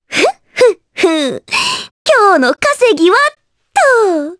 Requina-Vox_Victory_jp.wav